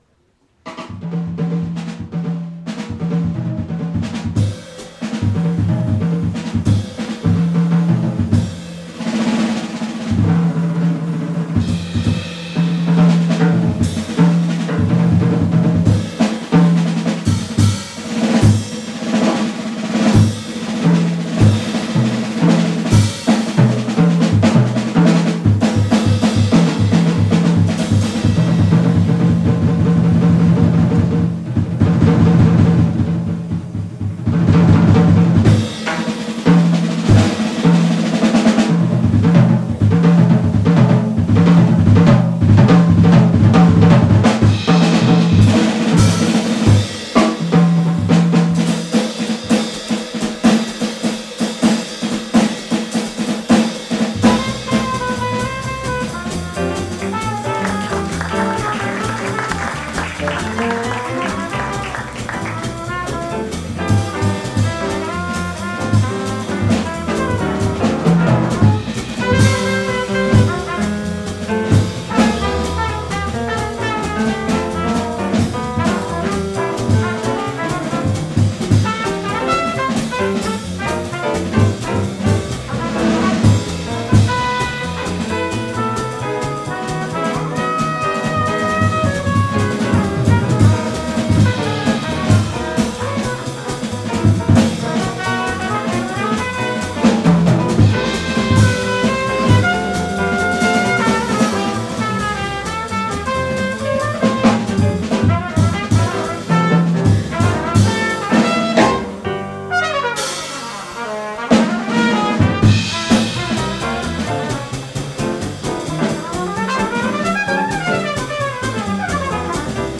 jazz standards
Bass
Piano
Trumpet